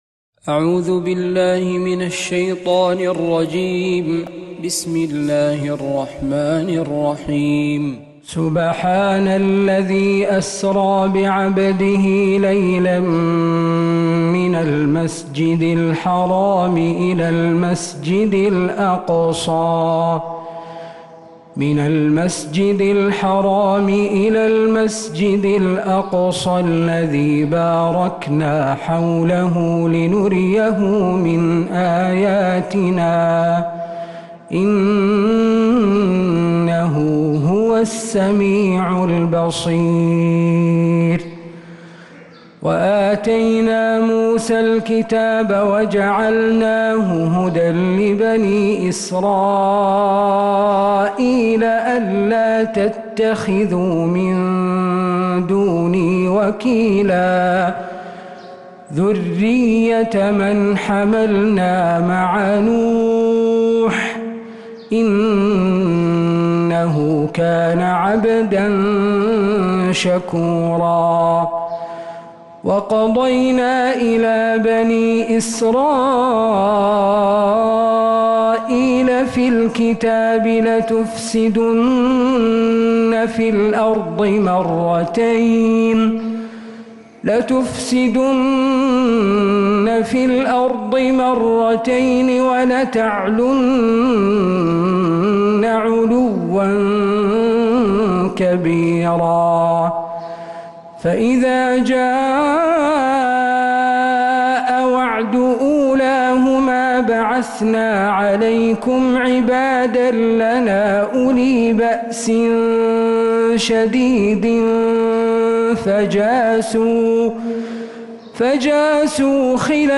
سورة الإسراء كاملة من فجريات الحرم النبوي